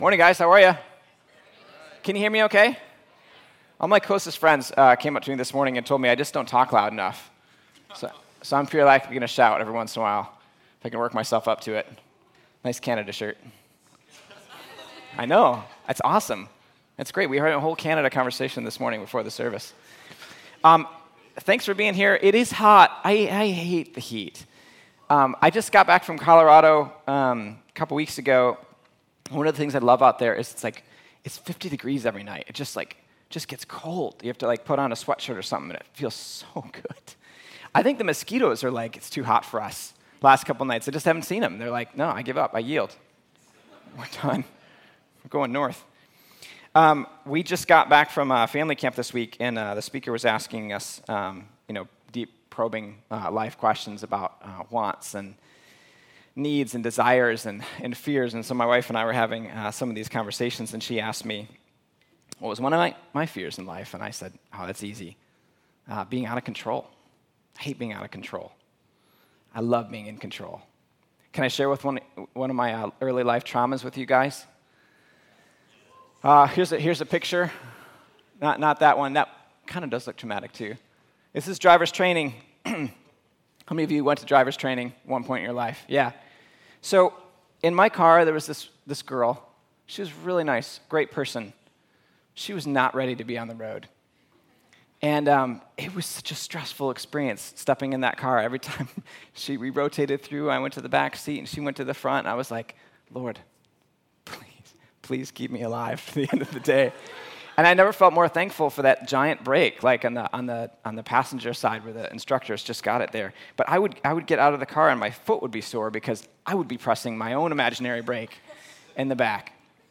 Audio Sermon Save Audio https